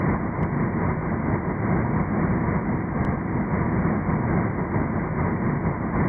hover.wav